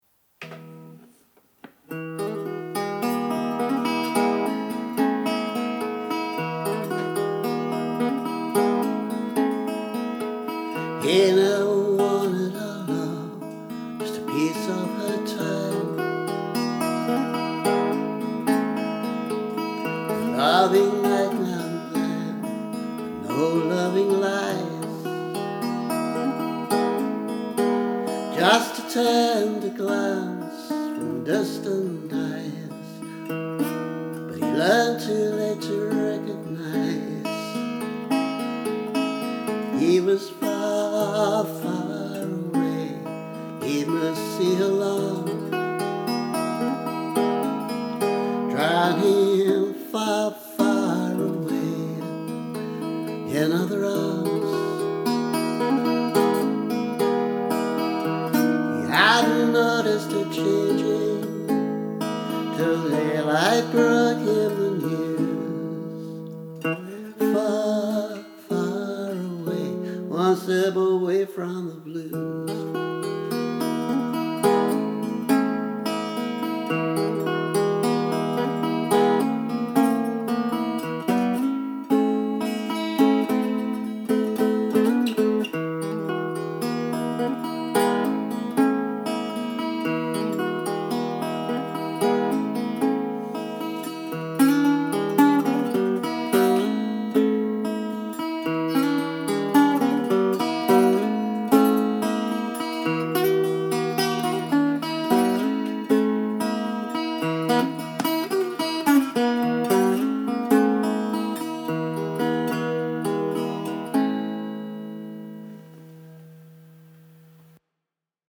rough sketch with high-strung guitar instead of normal acoustic